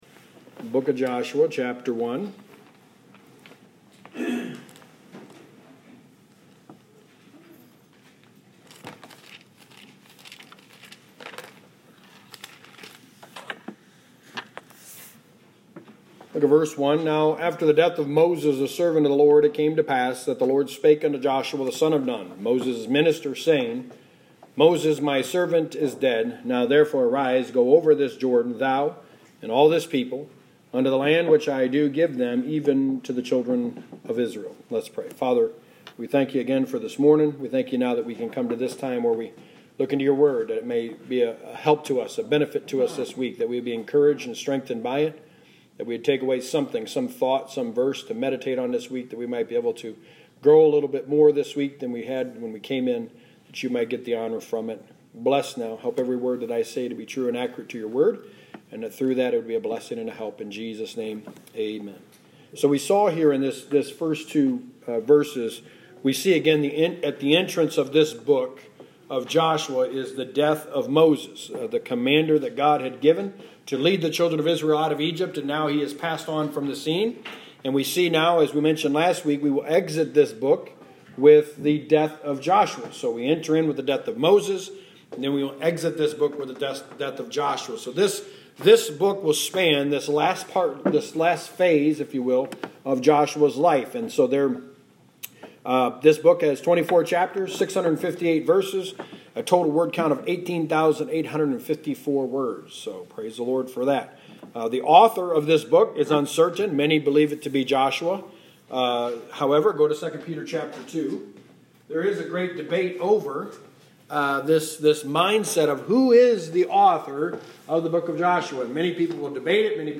The Book of Joshua: Sermon 2
Service Type: Sunday Morning